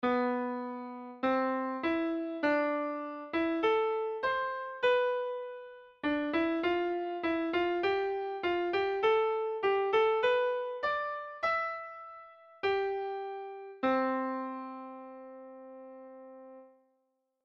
dominante-tonica2.mp3